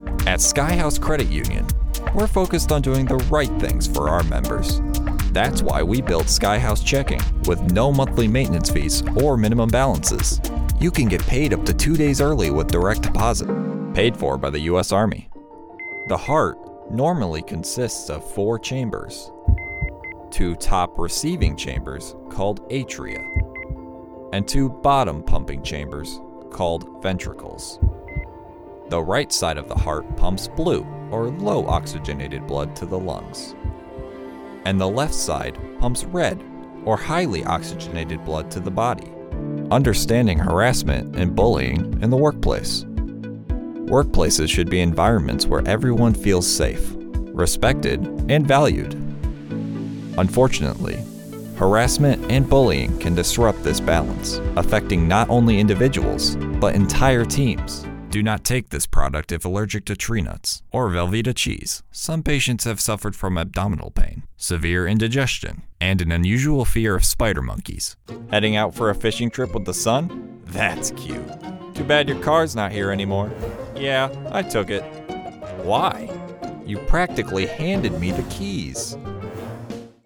PRO DEMO - Conversational, Authentic, Explainer, Authoritative, Quirky
English - Midwestern U.S. English
Young Adult
Middle Aged
- Broadcast-quality home studio, reviewed by a professional audio engineer